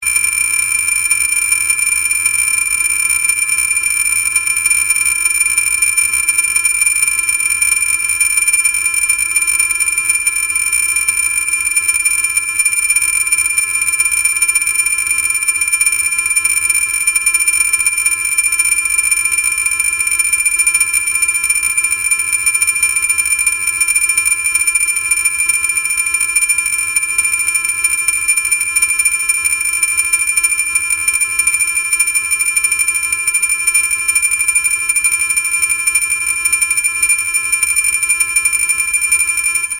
アラーム-06は、アナログ目覚まし時計の音を再現した音で鳴ります。
また、高音で鳴るため、遠くからでも聞こえるため、緊急時に役立ちます。